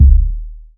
KICK.81.NEPT.wav